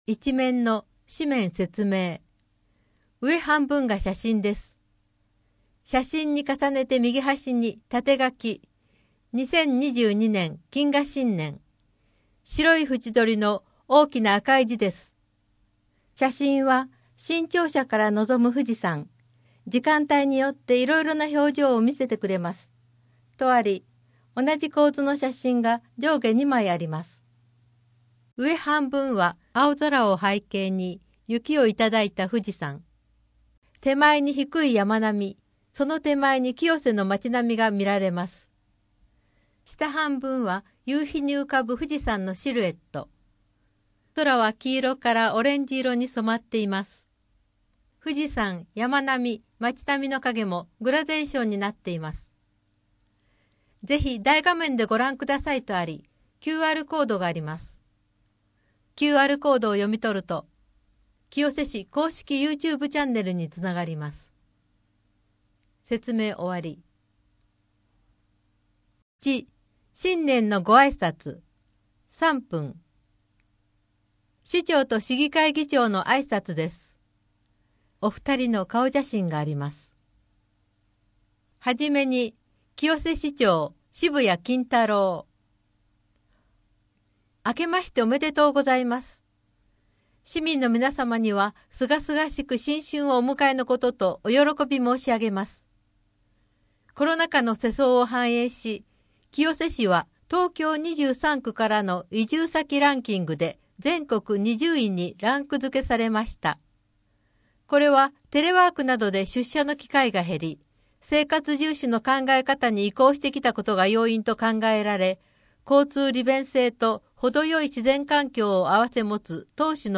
福祉法律専門相談 寄付・寄贈 郷土博物館のイベント 年中行事「小正月のまゆ玉飾り」 図書館からのお知らせ 年始の休館・開館のお知らせ 1・2月の子育て関連事業 1月の休日診療 24時間電話で聞ける医療機関案内 平日小児準夜間診療 令和4年1月1日号8面PDF （PDF 578.7KB） 声の広報 声の広報は清瀬市公共刊行物音訳機関が制作しています。